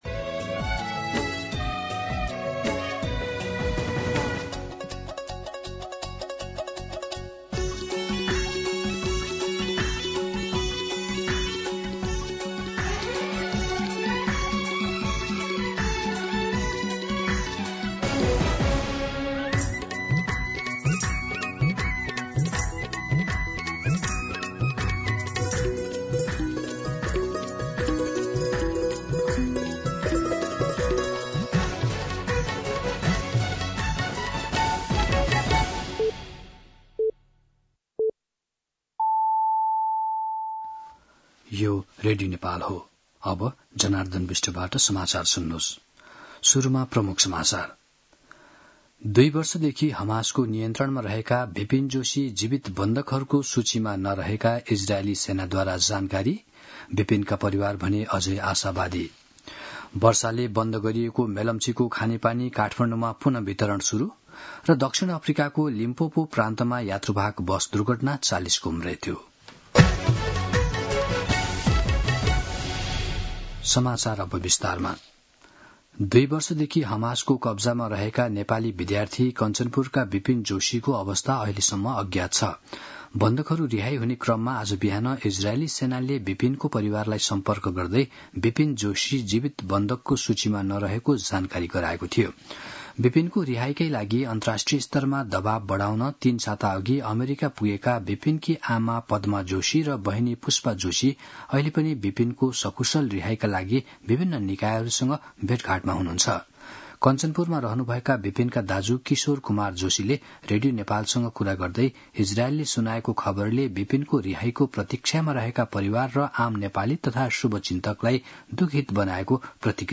दिउँसो ३ बजेको नेपाली समाचार : २७ असोज , २०८२